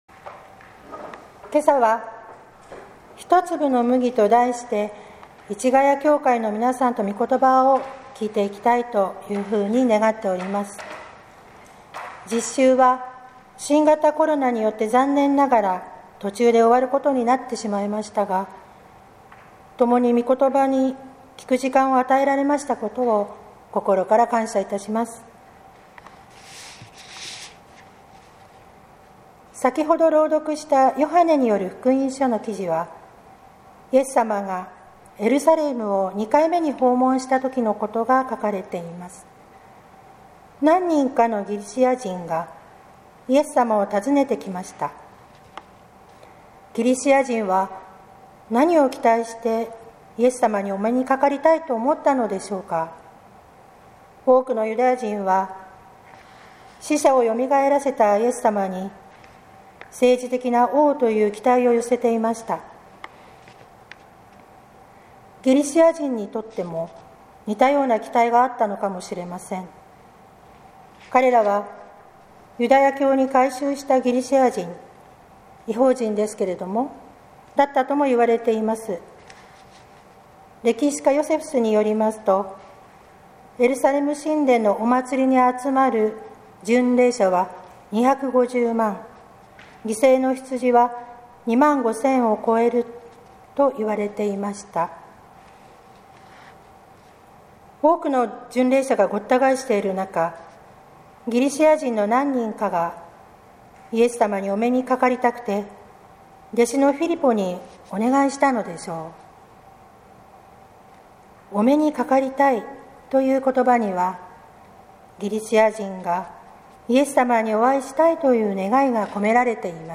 説教「一粒の麦」（音声版） | 日本福音ルーテル市ヶ谷教会